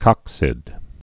(kŏksĭd)